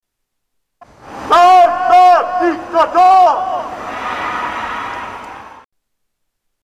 Shouting from The Rooftops